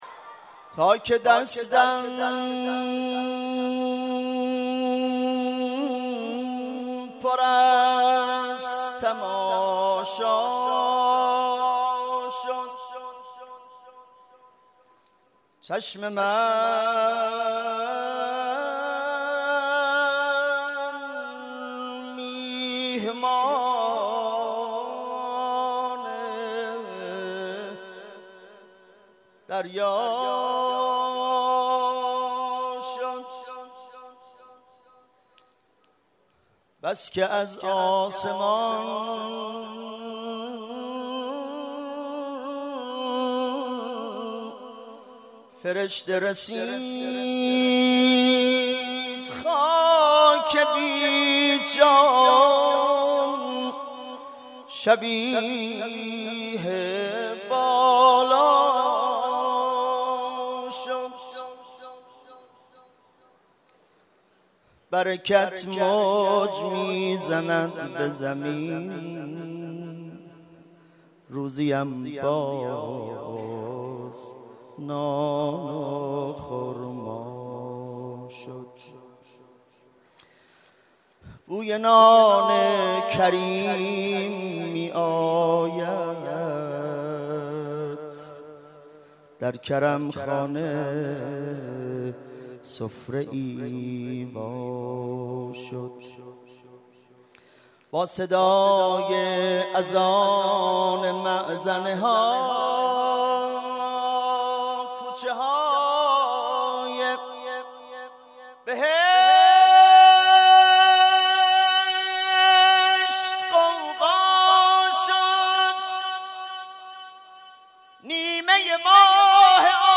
03.madh.mp3